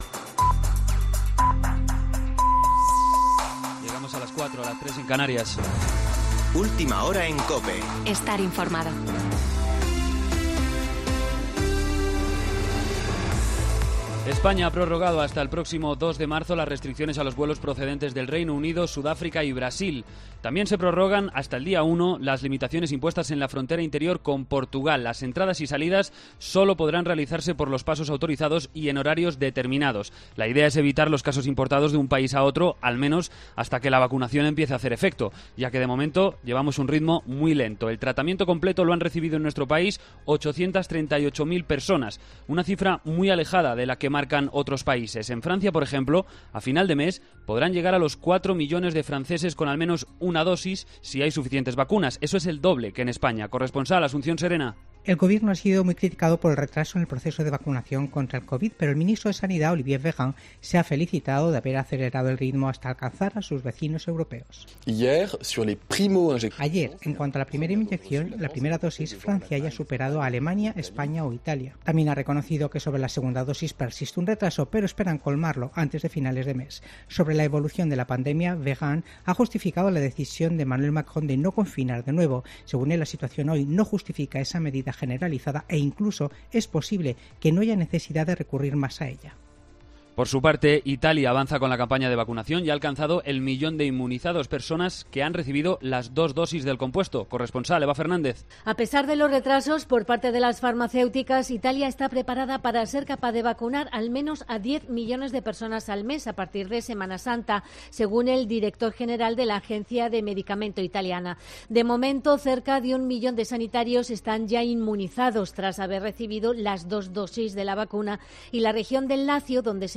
Boletín de noticias COPE del 10 de febrero de 2021 a las 04.00 horas